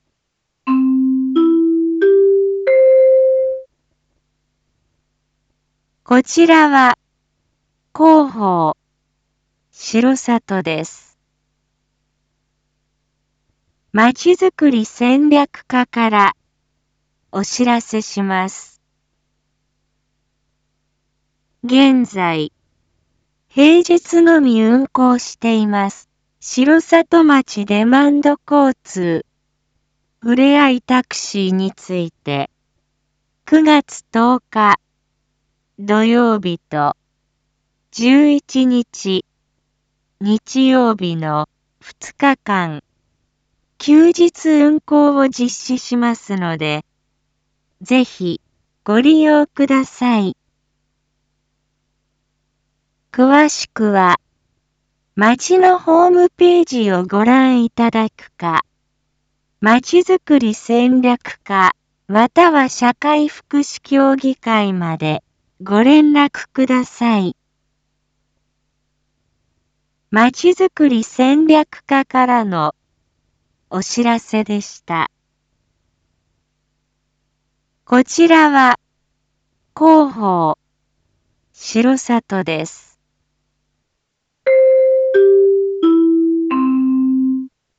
一般放送情報
Back Home 一般放送情報 音声放送 再生 一般放送情報 登録日時：2022-09-06 19:01:29 タイトル：R4.9.6 19時放送分 インフォメーション：こちらは広報しろさとです。